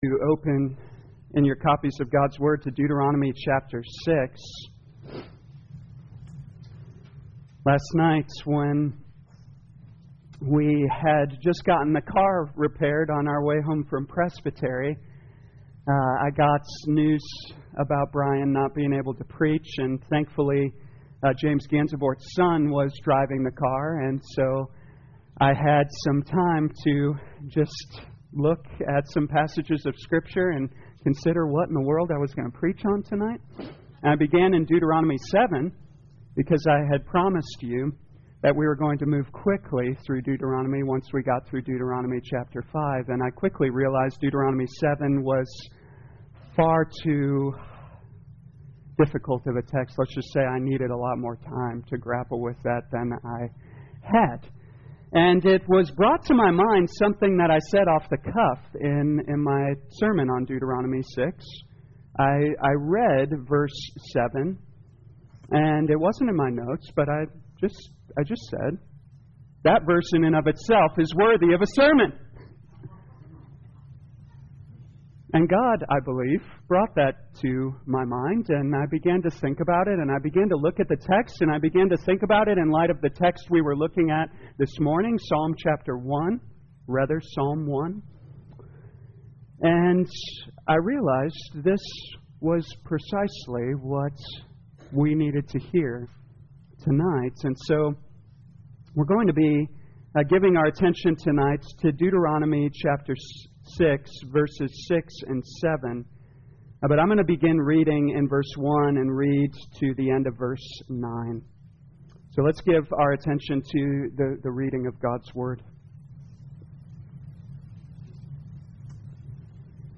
2022 Deuteronomy The Law Evening Service Download